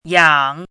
chinese-voice - 汉字语音库
yang3.mp3